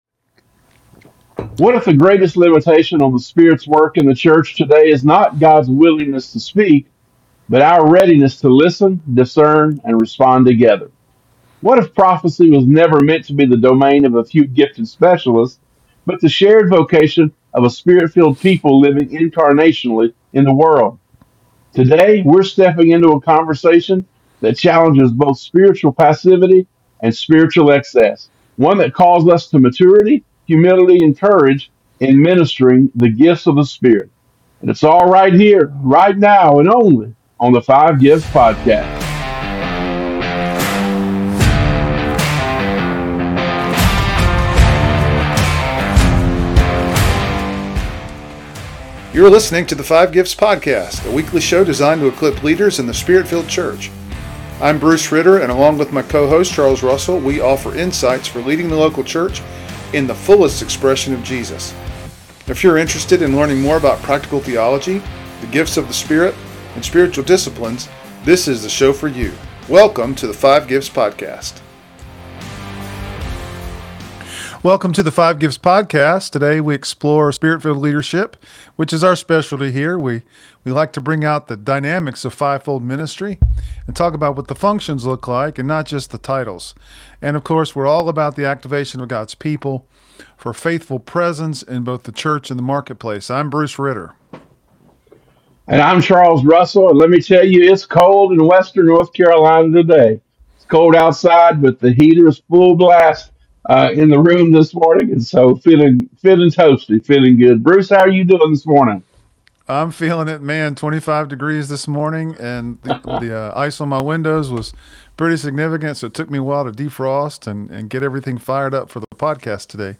This conversation equips leaders and